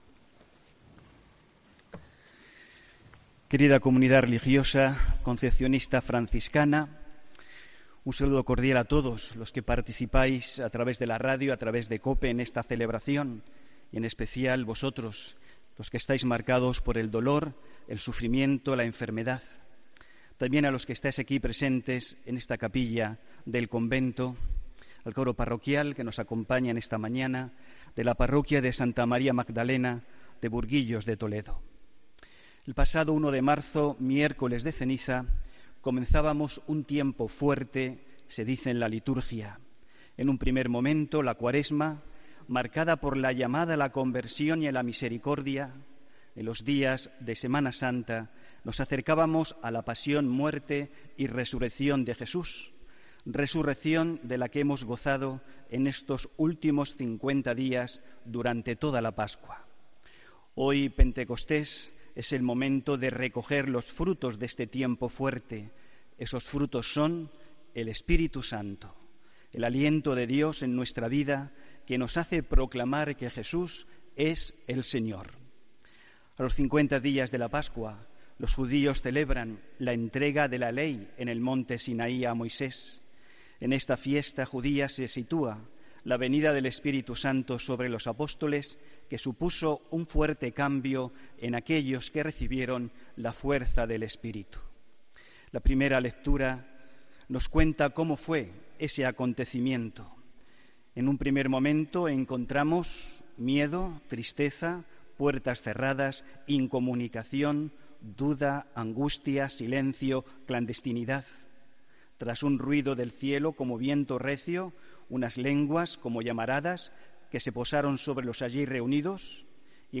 Homilía 4 de junio de 2017